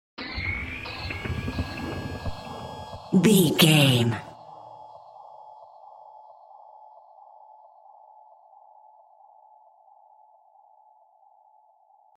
Middle Witch Stinger.
In-crescendo
Atonal
scary
ominous
suspense
eerie
Horror Synths
Scary Piano
Scary Strings